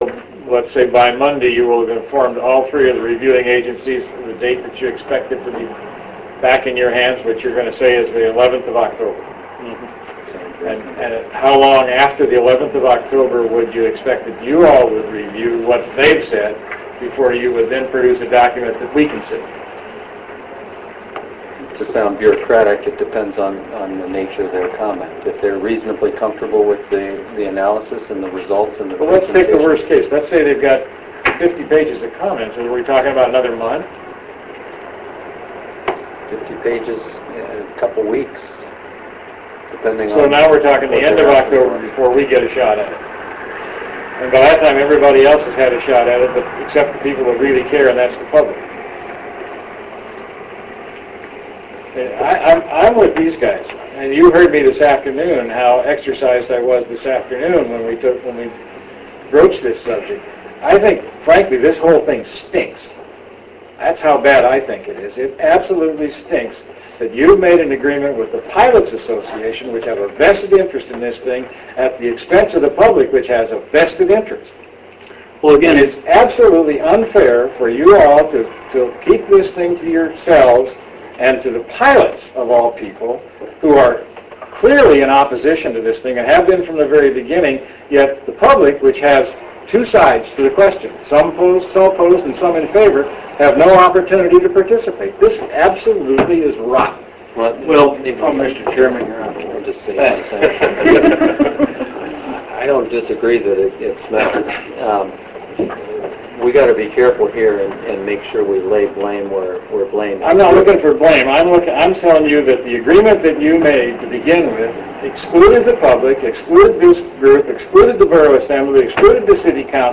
During a regular meeting of the Ketchikan Economic Development Authority (KEDA) Thursday night
Remarks